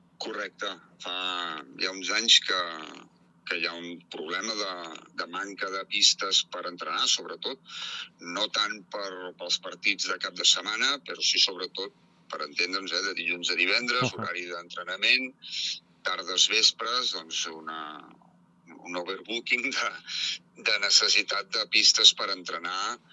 Joan Carles Batanés, alcalde de Sant Fruitós, explicava els detalls al programa Esport i Punt.